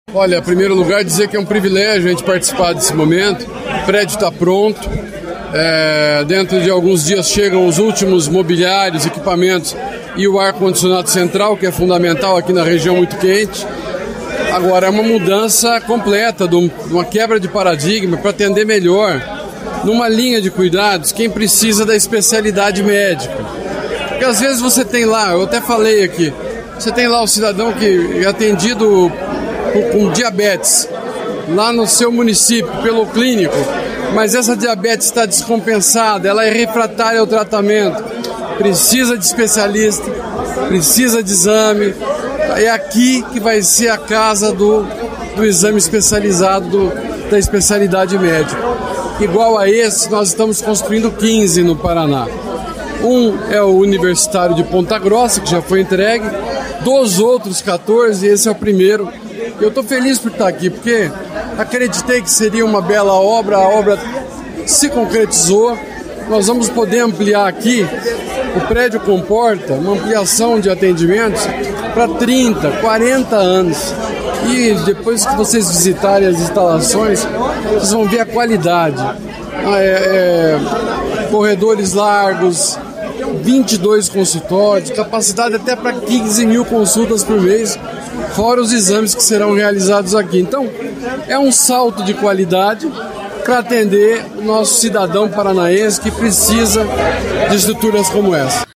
Sonora do secretário da Saúde, Beto Preto, sobre a inauguração do Ambulatório Médico de Especialidades em Cianorte